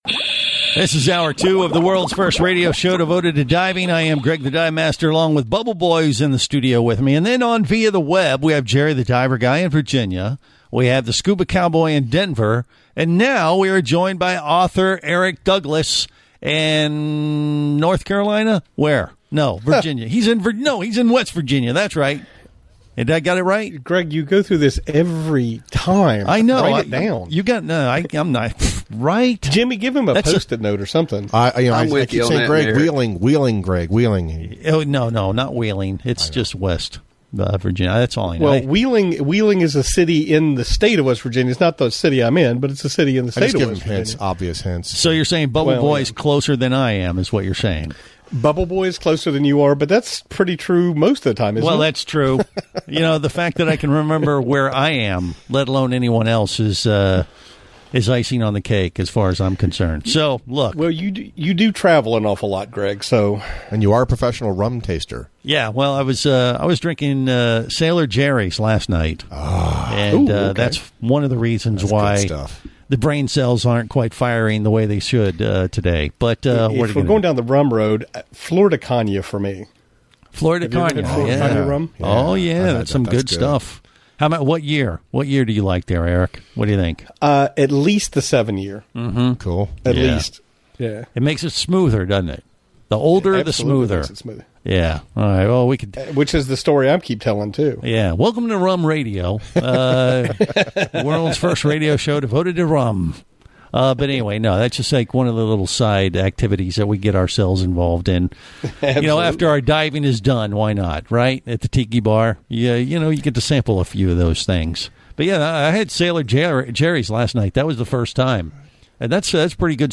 appeared on Scuba Radio talking about the release of the new boxed set of the first five Mike Scott adventure novels.